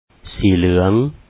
sǐi-luaŋ Yellow